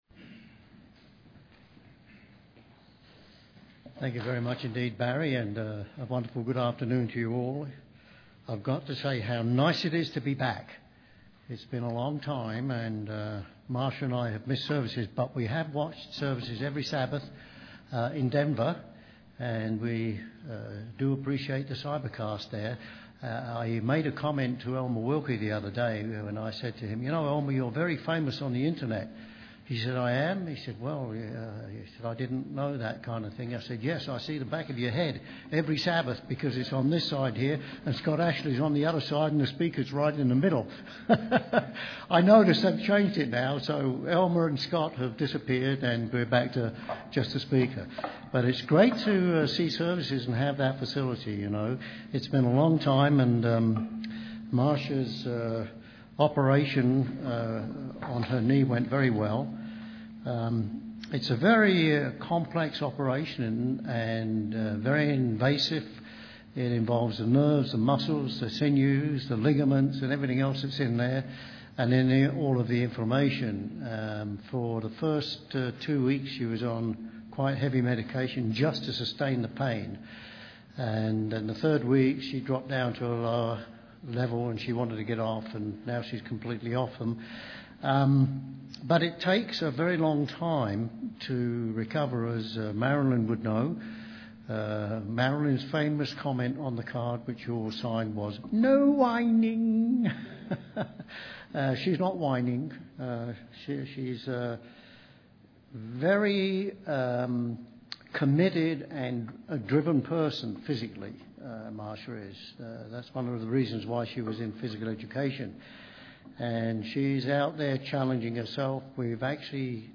Sermons
Given in Colorado Springs, CO